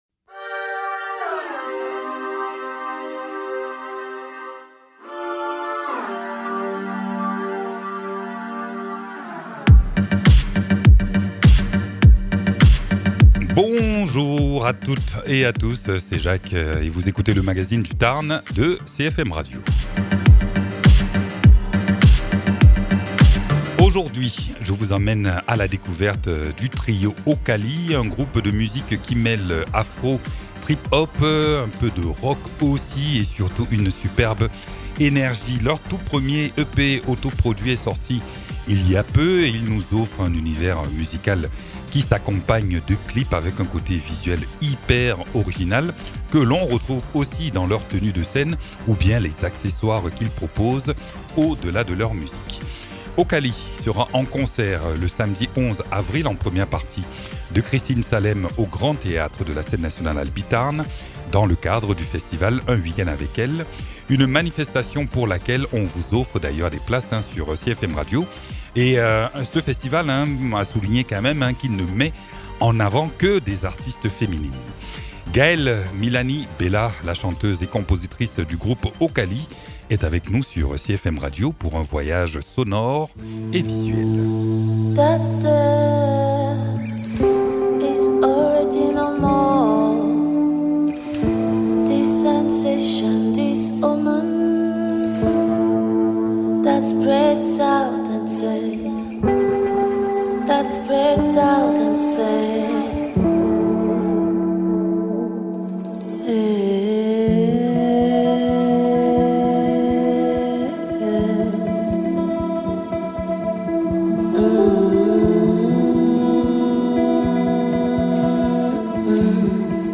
À l’occasion de leur concert le samedi 11 avril au Grand Théâtre de la Scène nationale Albi-Tarn dans le cadre du festival Un Week-end avec elles, le groupe Okali se confie au micro de CFM Radio. Un trio en plein essor qui mêle afro trip-hop, rock et sonorités métissées, porté par une identité artistique forte et une énergie créative en autoproduction. Une rencontre pour découvrir l’univers singulier d’un groupe émergent qui fait déjà beaucoup parler de lui sur scène.